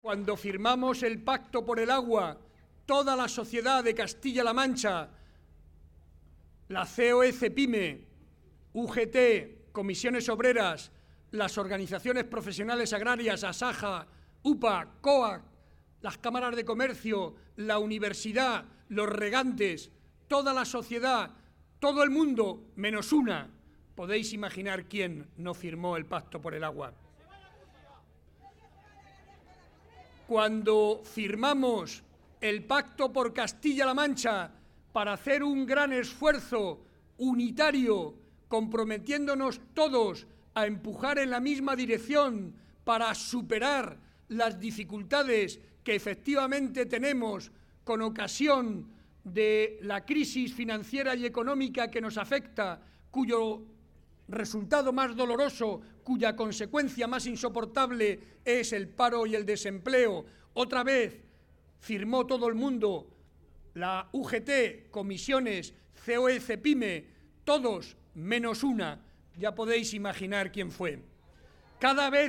Ante 2.000 personas, en el Paseo del Prado de Talavera de la Reina, junto al alcalde y candidato a la reelección, José Francisco Rivas, Barreda se limitó a explicar a los asistentes su proyecto para Castilla-La Mancha basado en compromisos concretos como el anunciado, en una forma de gobernar desde la cercanía y en la dedicación exclusiva a los castellano-manchegos. Además de energías limpias y renovables, el presidente Barreda habló de agua, de Educación, Sanidad y servicios sociales.